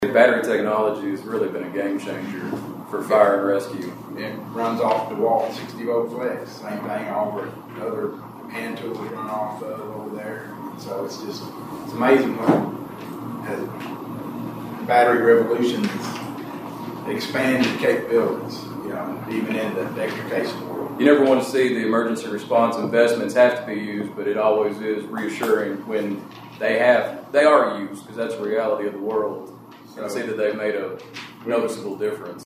Magistrates in Caldwell County learned about significant upgrades to local emergency equipment—specifically, battery-powered rescue tools—during this week’s Fiscal Court meeting.